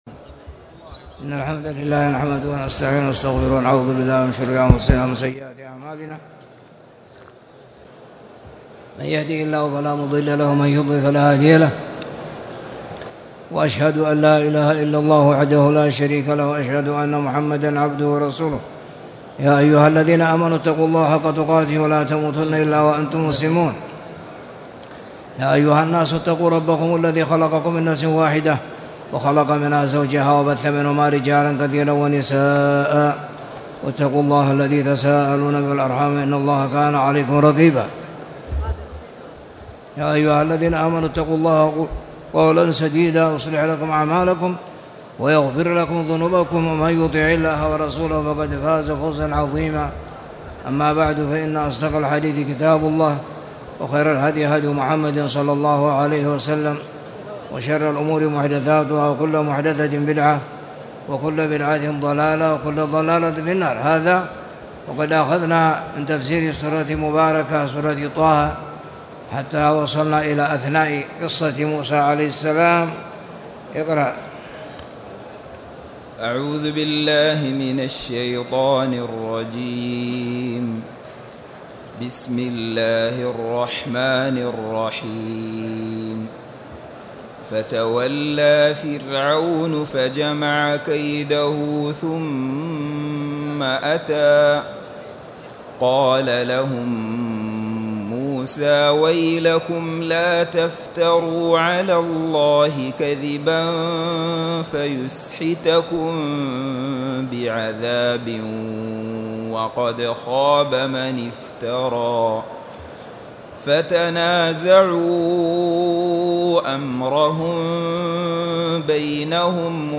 تاريخ النشر ٢ رجب ١٤٣٨ هـ المكان: المسجد الحرام الشيخ